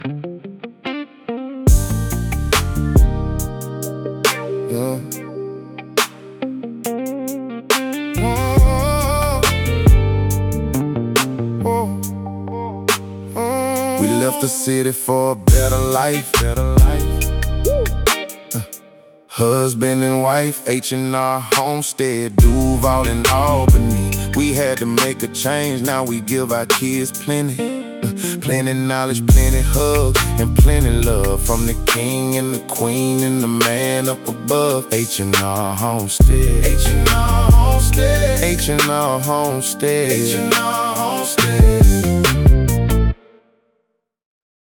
Intro Music
{ Company Jingle } April 2026